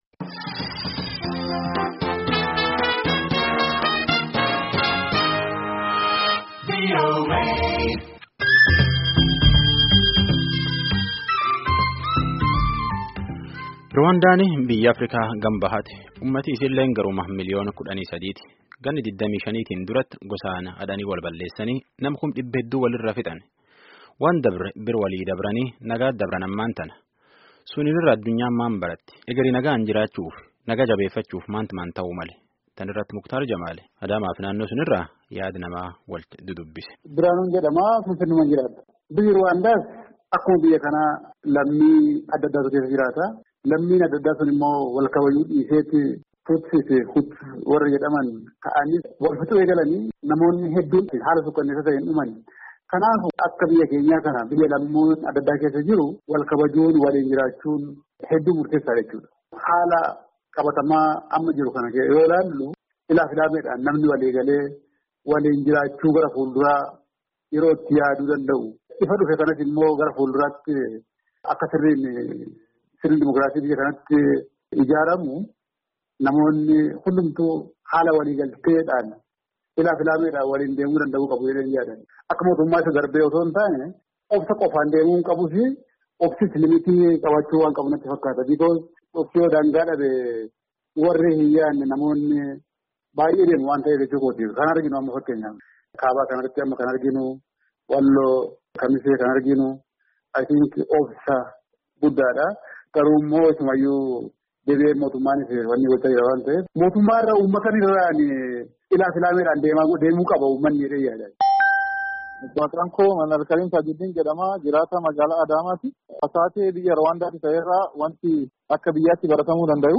dhimma kana irratti namoota isa mudatan adda addaa dubbisee jiraa.